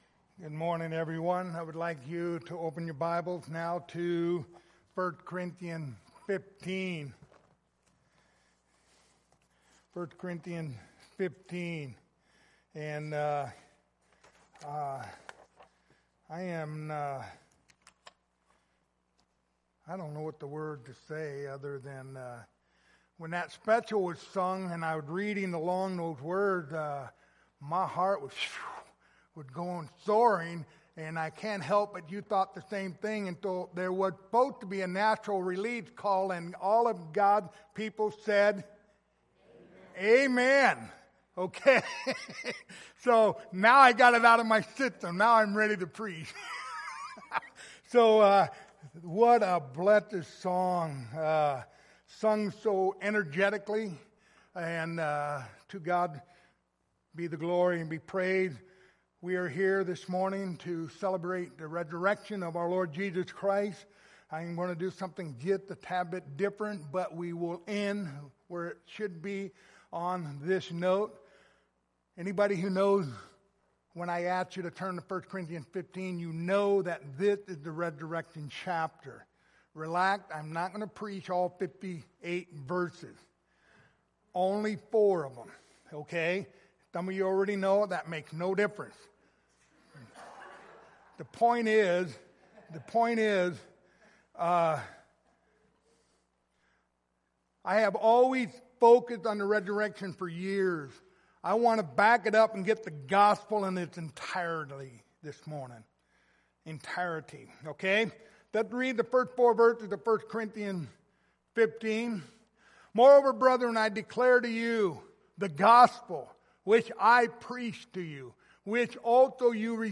Resurrection Passage: 1 Corinthians 15:1-4 Service Type: Sunday Morning Topics